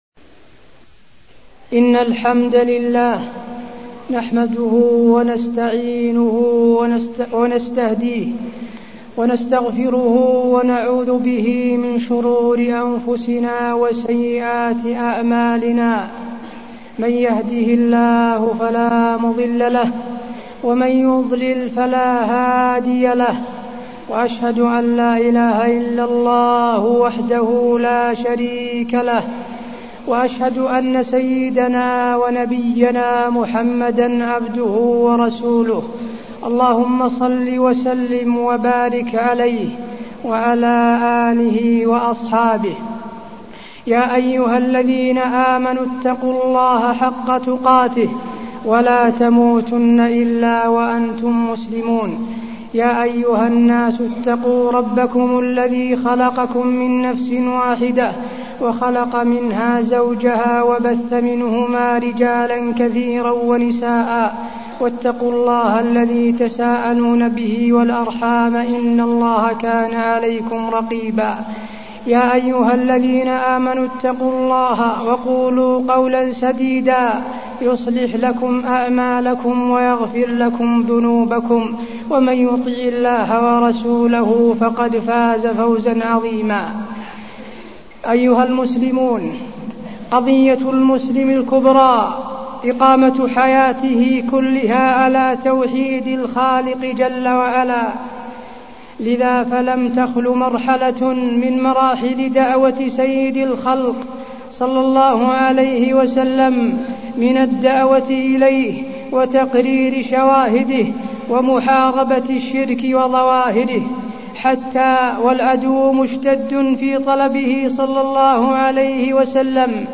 تاريخ النشر ٢٦ ذو القعدة ١٤٢٠ هـ المكان: المسجد النبوي الشيخ: فضيلة الشيخ د. حسين بن عبدالعزيز آل الشيخ فضيلة الشيخ د. حسين بن عبدالعزيز آل الشيخ وقفات مع الحج